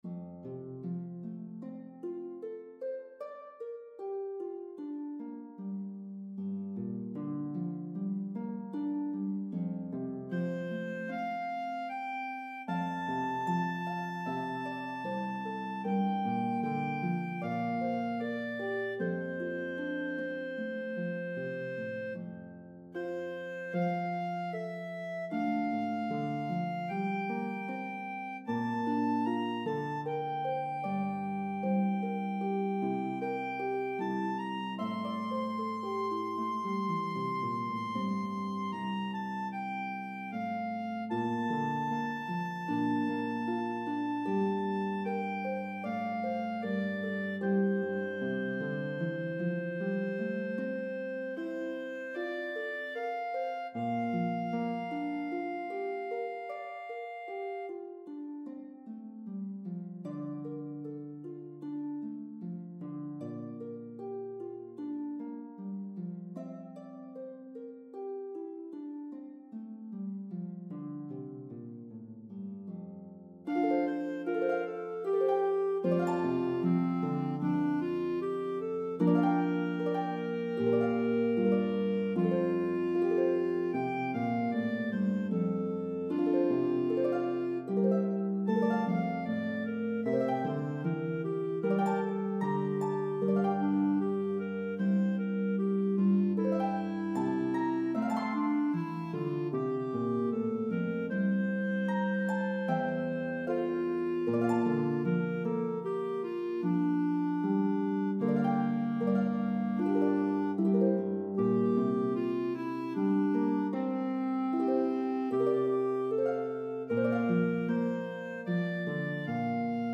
Harp and Clarinet version